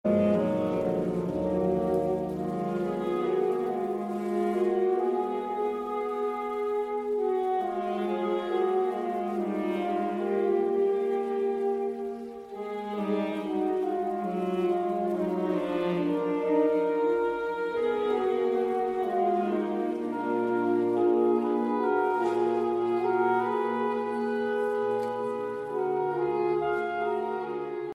– arrangiert für neun Saxophone solo plus Pauken / Perkussion
(Alternativ: Arrangement für acht Saxophone, Pauken, Chor (SAATB))
Magnifisax verbindet Barock mit Jazz, die Strenge Bachscher Musik mit der Freiheit persönlichen Ausdrucks.
Hörbeispiele MagnifiSAX mit Chören der Marienkantorei Lemgo (Live 8.11.2019):